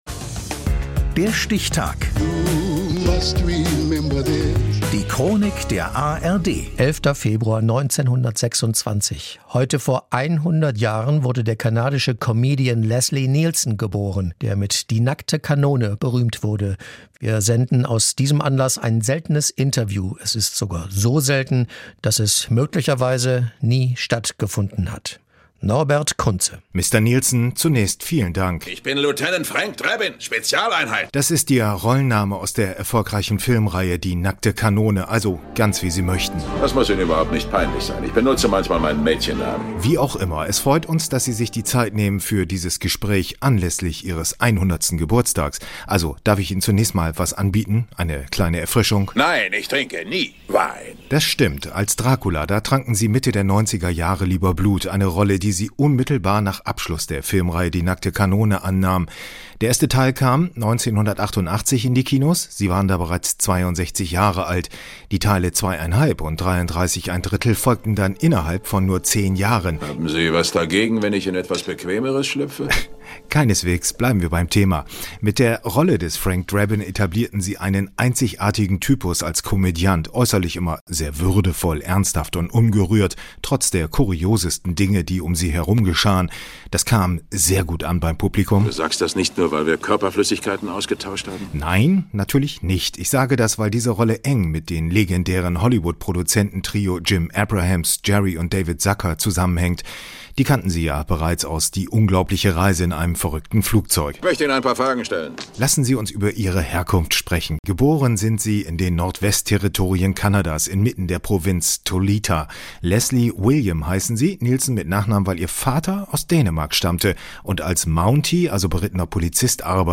diesem Anlass ein seltenes Interview, es ist sogar so selten, dass